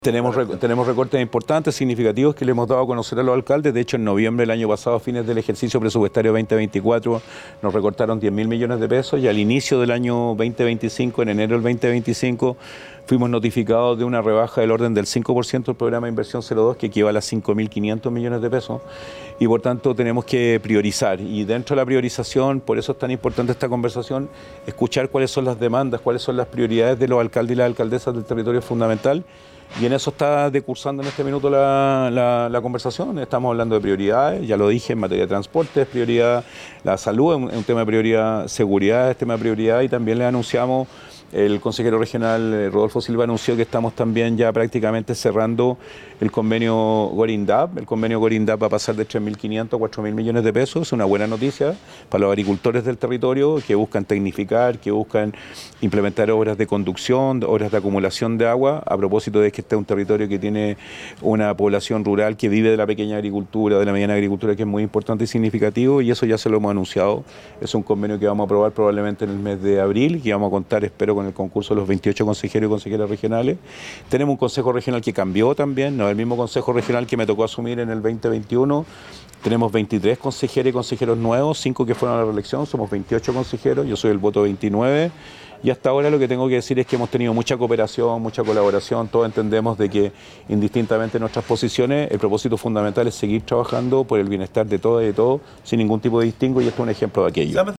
Por eso, el encuentro en el Buen Pastor fue brutalmente sincero y se aplicó la palabra PRIORIZAR, como lo dijo el propio Mundaca.